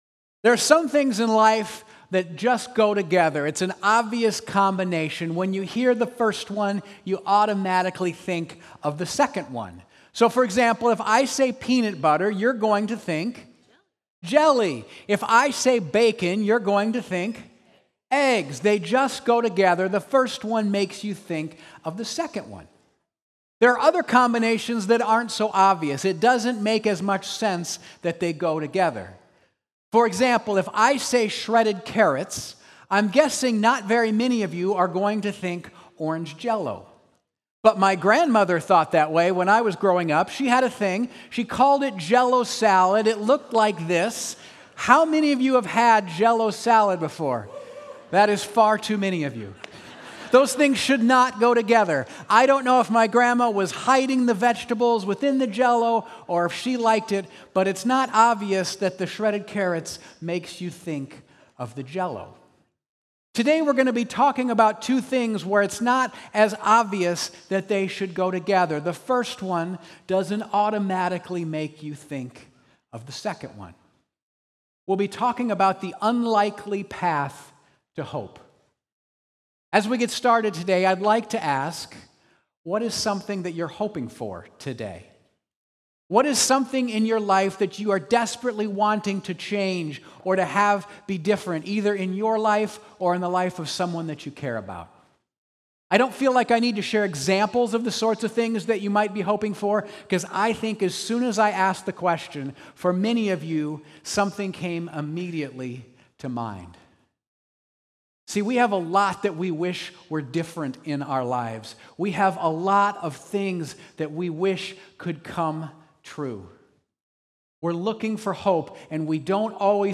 Path To Hope | Sermons | Central Church | Sioux Falls, SD